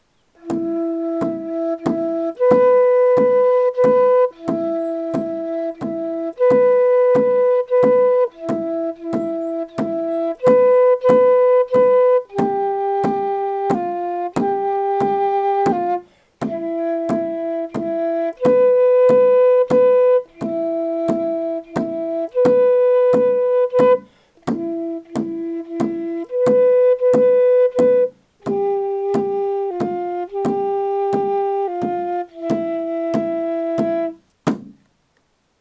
Chant Melody audio (no words)
holda_chant.mp3